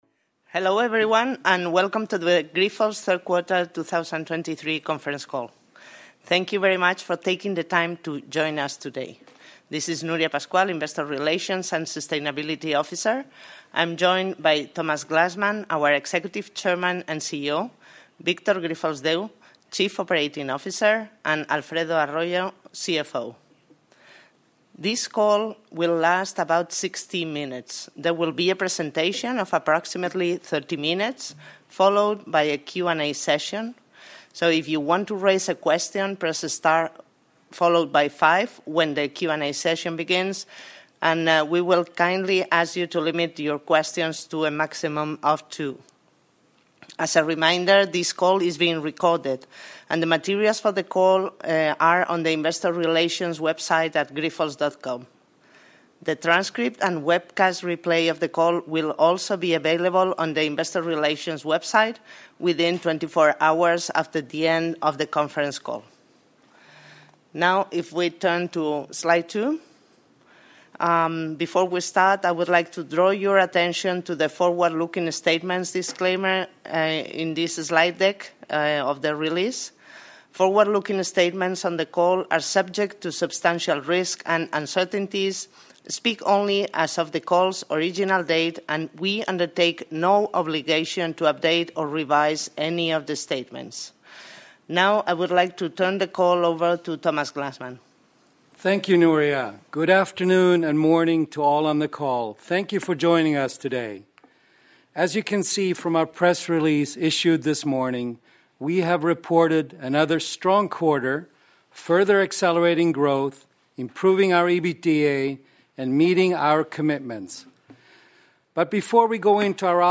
Conference call